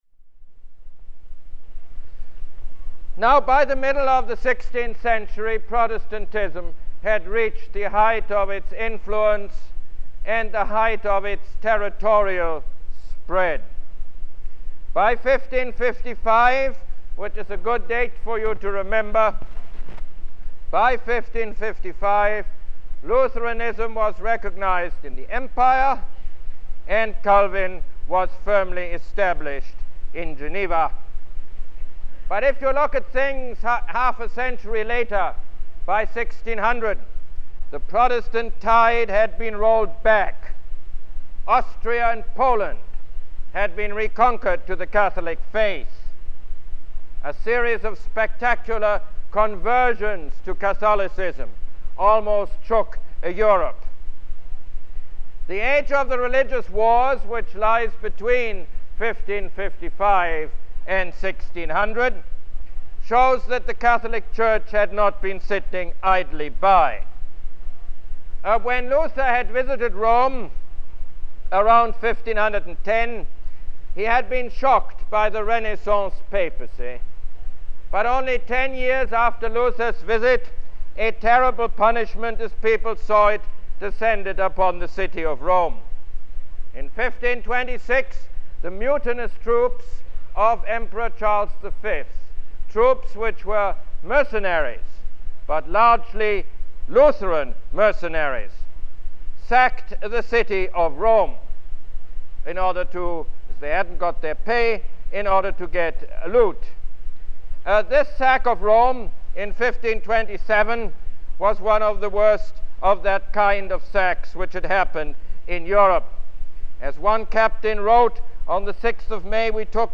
Lecture #7 - The Catholic Reformation